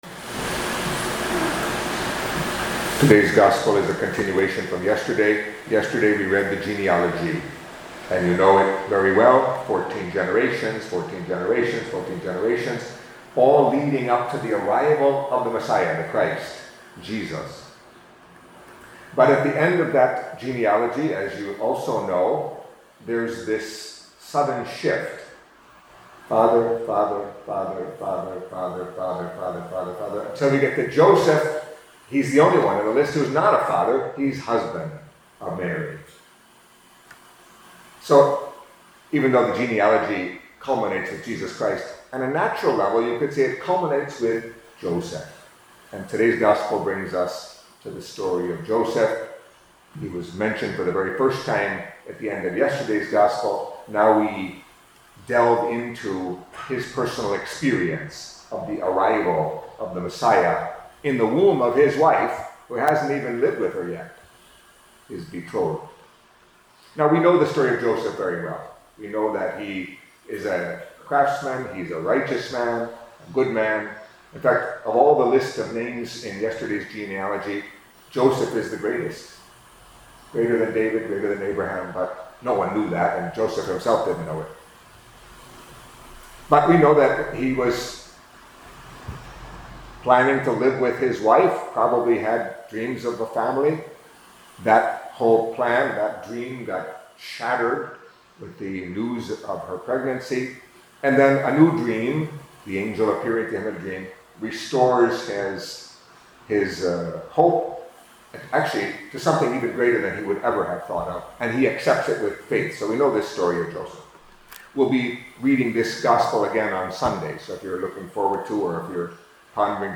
Catholic Mass homily for Thursday of the Third Week of Advent